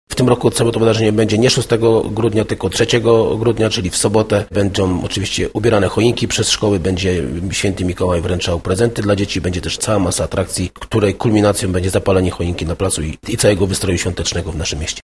W Wieluniu w sobotę rozbłysną ozdoby świąteczne na ulicach miasta i choinka na placu Legionów. Z najmłodszymi mieszkańcami spotka się też Święty Mikołaj – zapowiada burmistrz Paweł Okrasa: